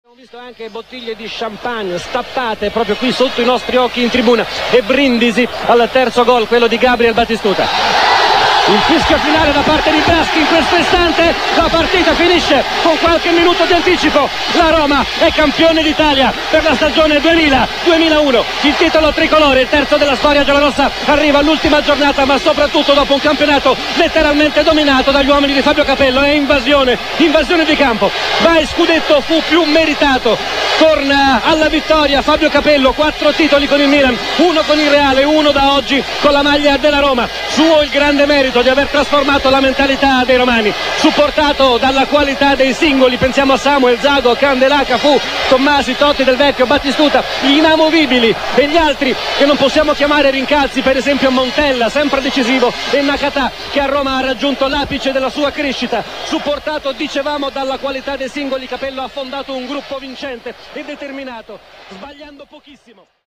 L'annuncio della Roma campione d'Italia di Riccardo Cucchi